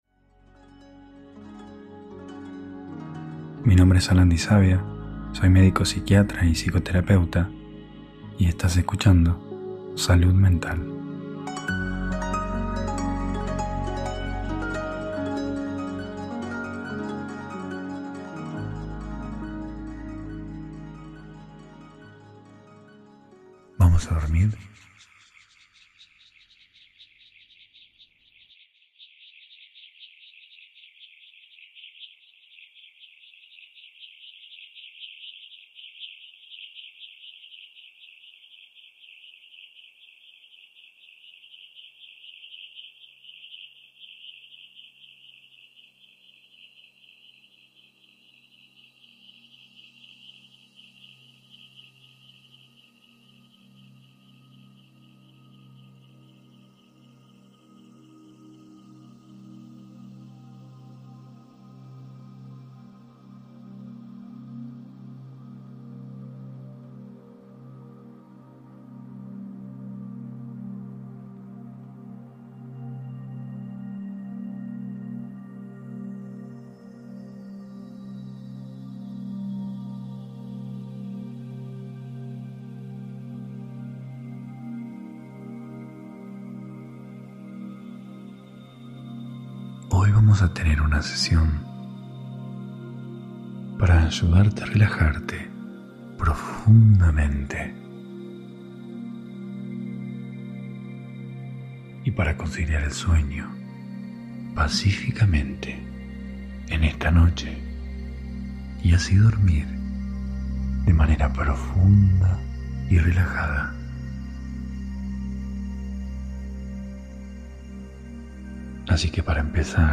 Hipnosis guiada para dormir.